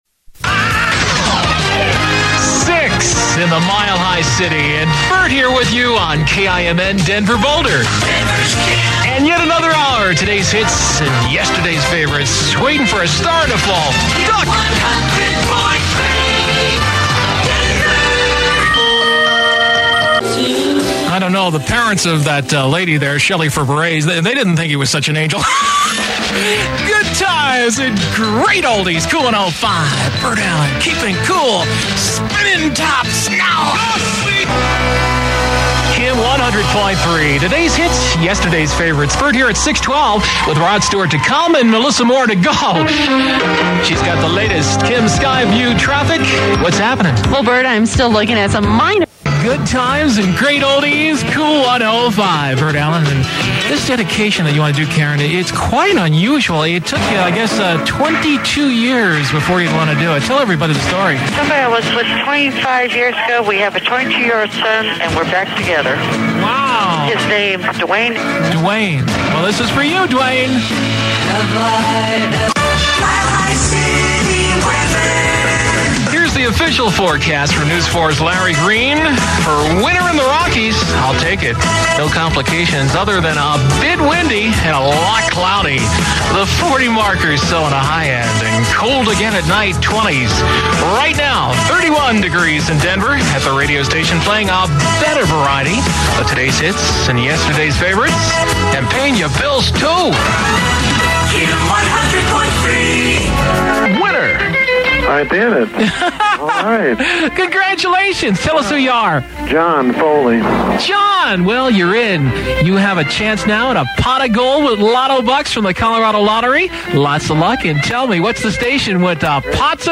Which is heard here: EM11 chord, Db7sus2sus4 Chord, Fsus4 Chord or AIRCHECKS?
AIRCHECKS